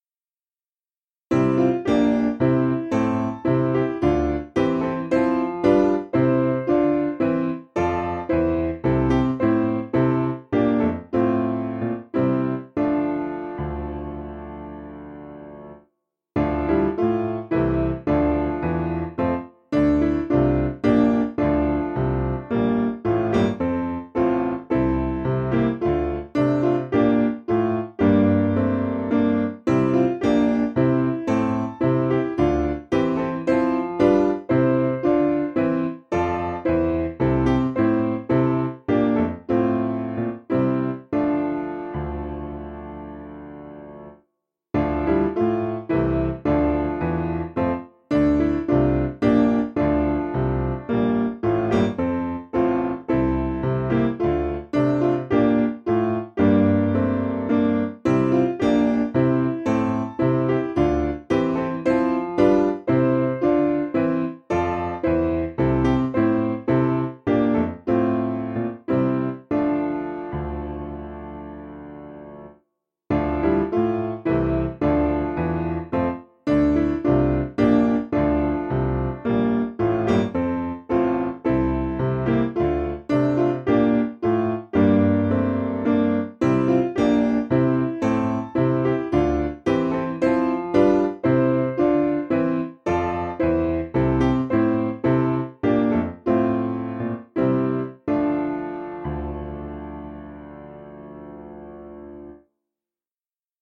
KING'S WESTON is a great tune marked by distinctive rhythmic structures and a soaring climax in the final two lines.